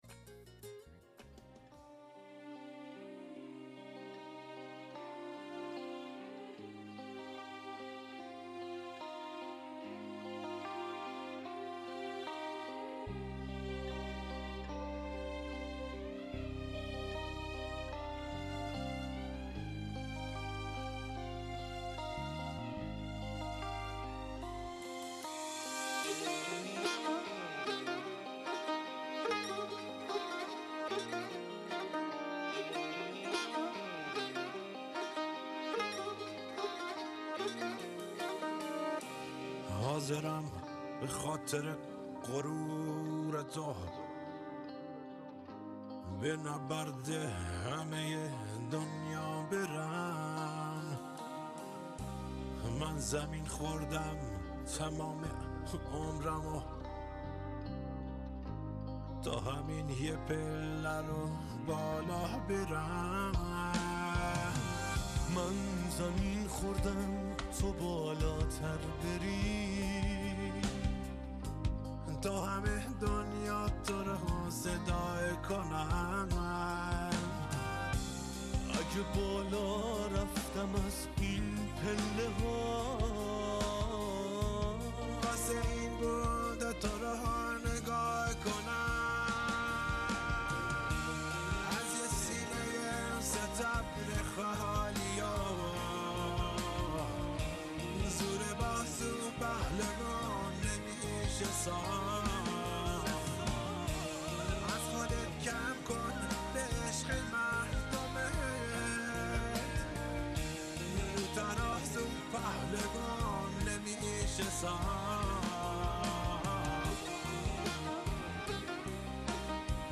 موسیقی پاپ